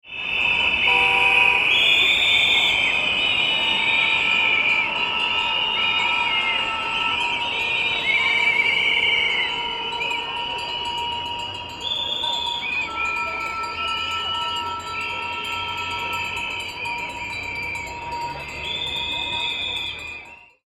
Street Protest Sound Effect – Whistling, Car Horns, Crowd Disapproval
Protest crowd sound with whistling, car horns, and angry reactions.
Genres: Sound Effects
Street-protest-sound-effect-whistling-car-horns-crowd-disapproval.mp3